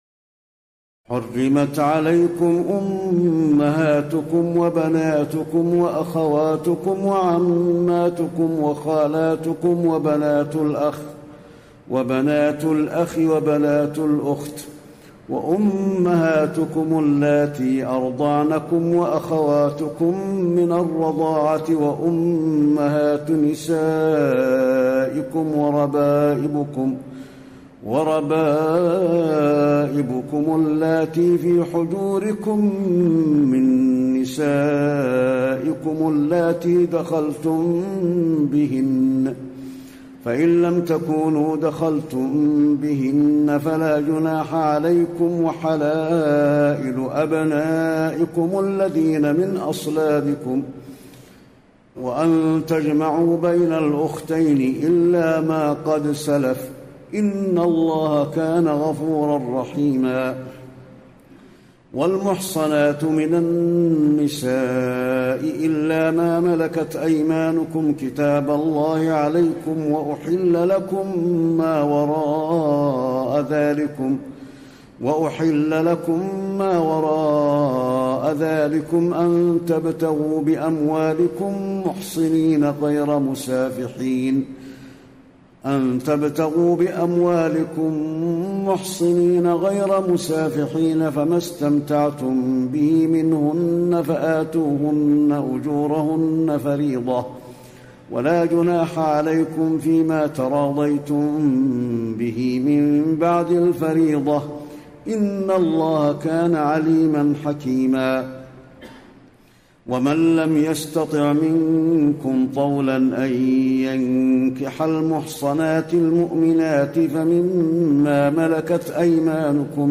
تراويح الليلة الخامسة رمضان 1434هـ من سورة النساء (23-87) Taraweeh 5 st night Ramadan 1434H from Surah An-Nisaa > تراويح الحرم النبوي عام 1434 🕌 > التراويح - تلاوات الحرمين